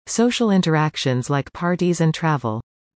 以下、設問１）〜４）の不正解答案文を音読したネイティブ音声を出題しました。
不正解答案の読み上げ音声
弱音の母音から始まる内容語は、前の子音とリンキングしやすい。
4.social_interactions_like_parti.mp3